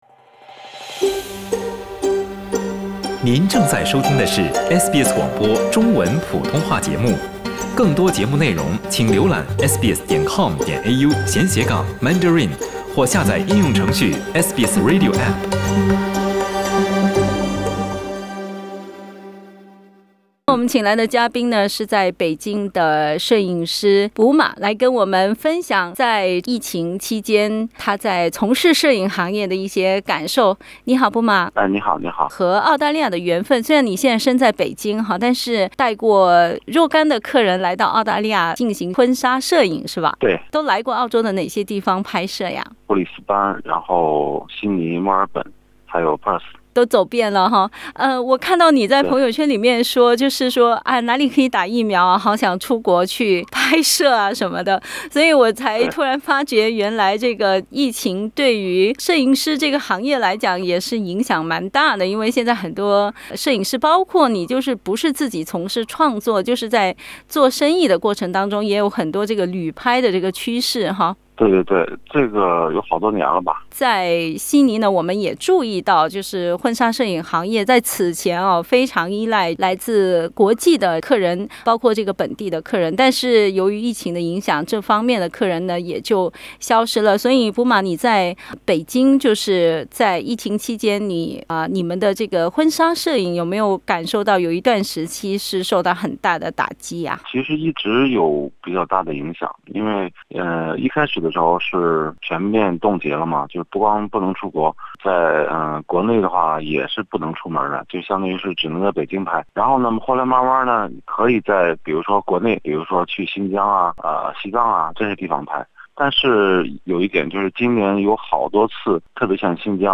（请听采访） 澳大利亚人必须与他人保持至少1.5米的社交距离，请查看您所在州或领地的最新社交限制措施。